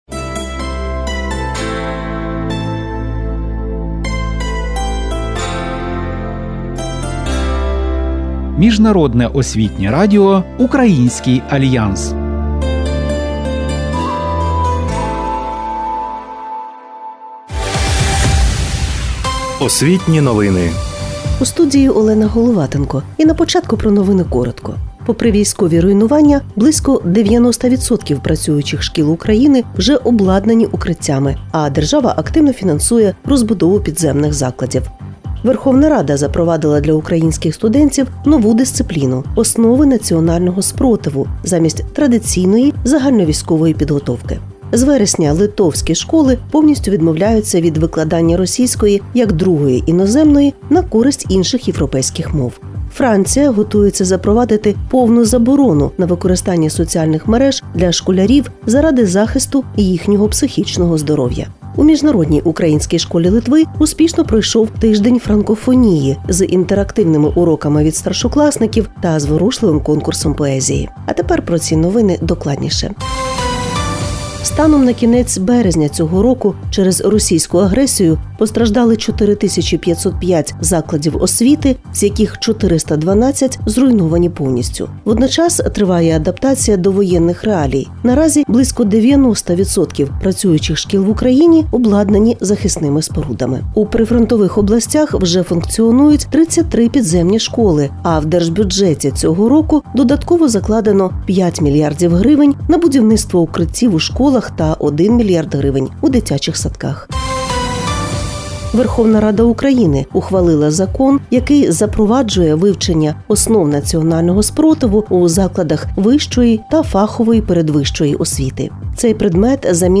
Освітні новини